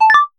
notification_sounds
friendly.mp3